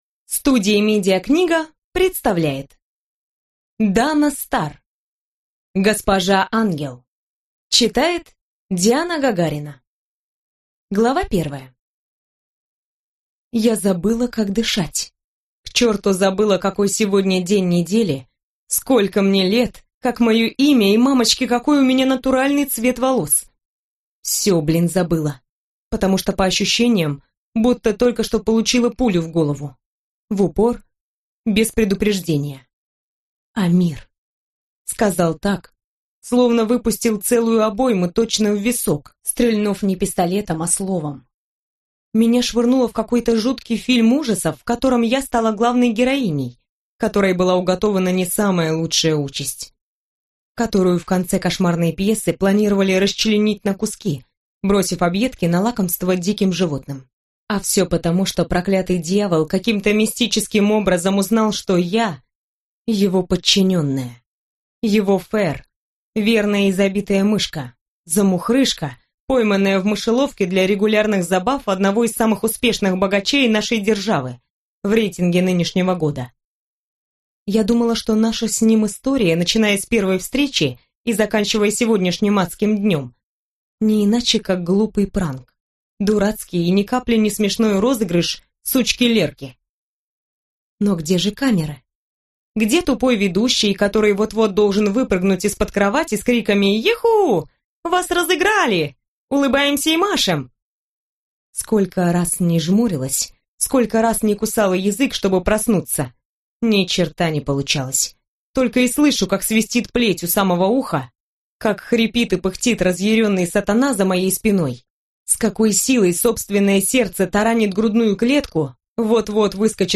Аудиокнига Госпожа Ангел | Библиотека аудиокниг